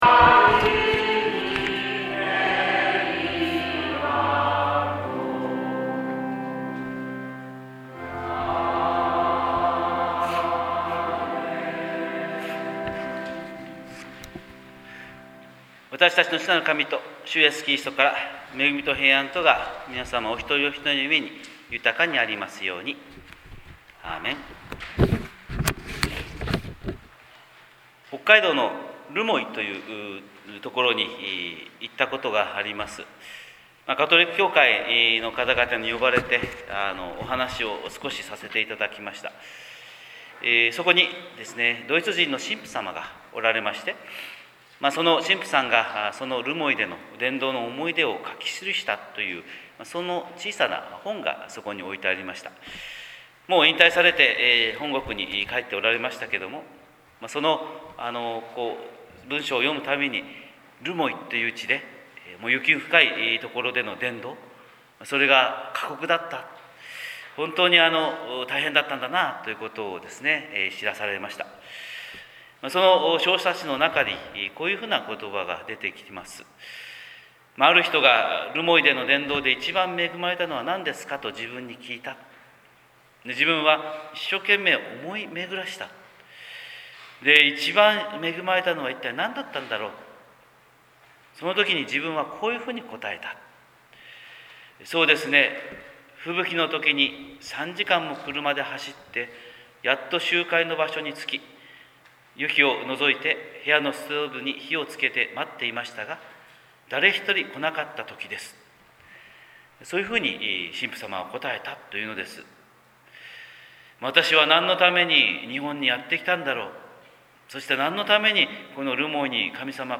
神様の色鉛筆（音声説教）
日本福音ルーテル教会（キリスト教ルター派）牧師の朝礼拝説教です！
待降節第１主日礼拝